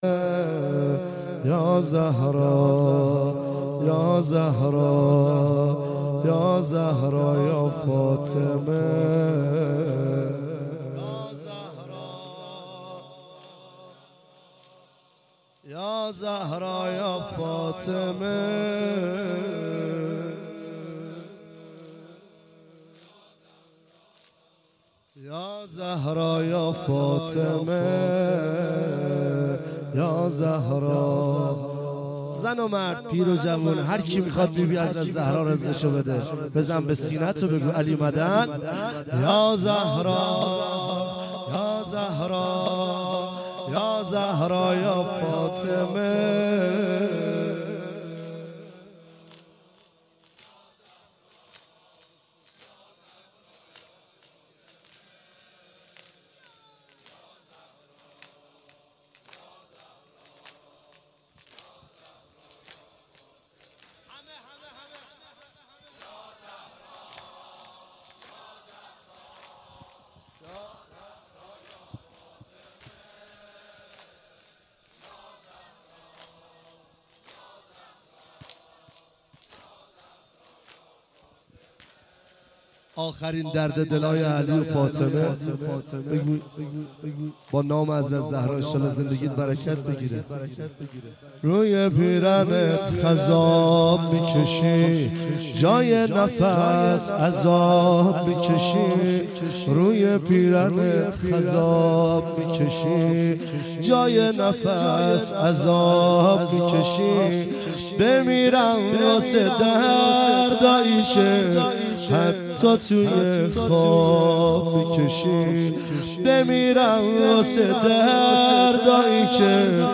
فاطمیه
زمینه مداحی